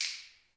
Index of /90_sSampleCDs/Roland L-CDX-01/PRC_Clap & Snap/PRC_Snaps